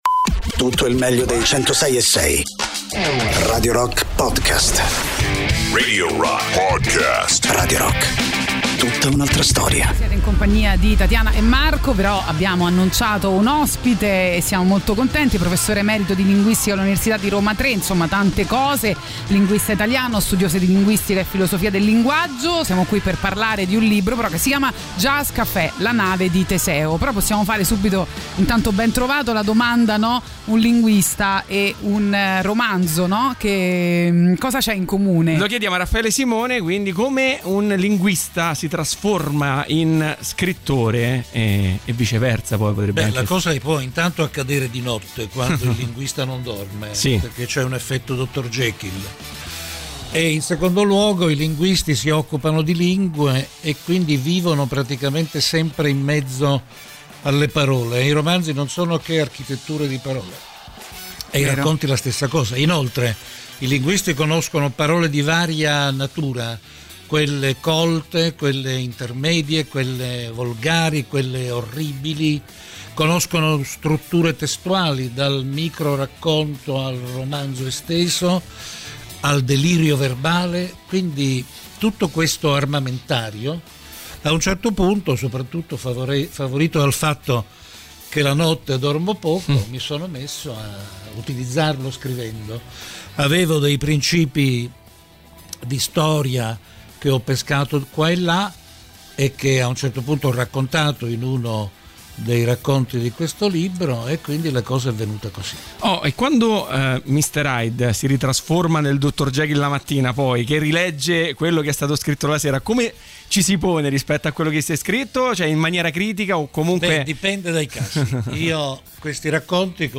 Interviste: Raffaele Simone (28-06-23)
ospite in studio